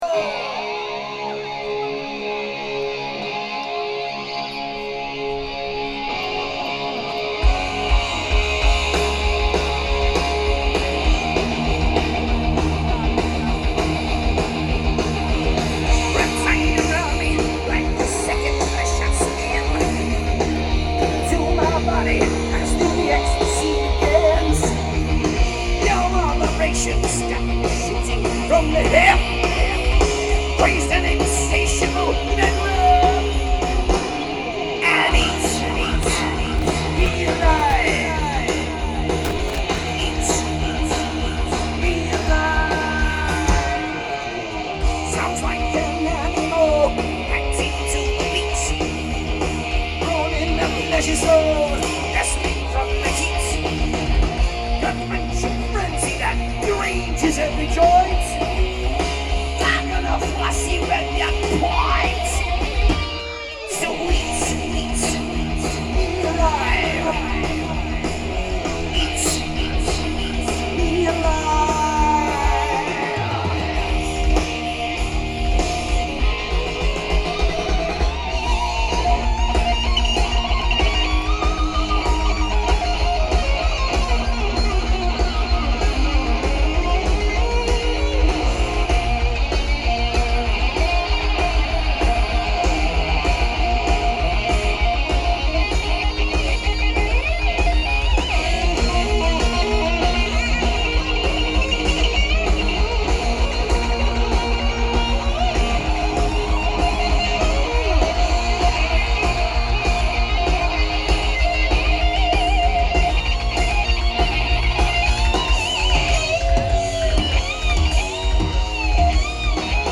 quality is very good.